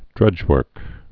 (drŭjwûrk)